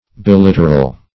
Search Result for " biliteral" : The Collaborative International Dictionary of English v.0.48: Biliteral \Bi*lit"er*al\, a. [L. bis twice + littera letter.]